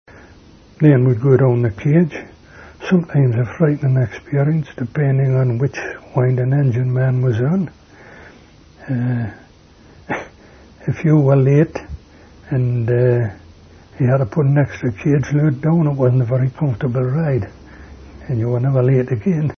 Narrative History: